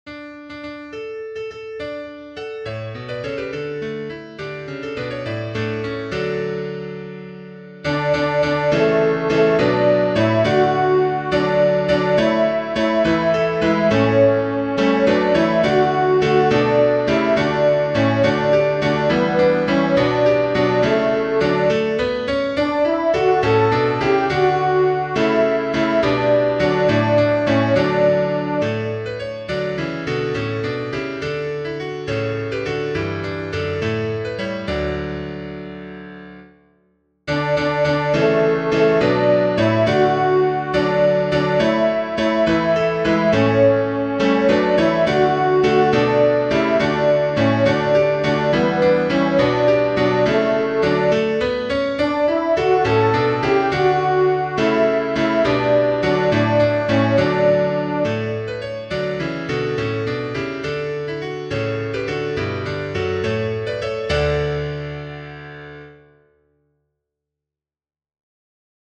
arranged by J. F. Dausch See the score in a new tab: People_Look_East Tutti (In the recordings below, the featured voice is a horn. Other parts are played softly.)
people_look_east-alto2.mp3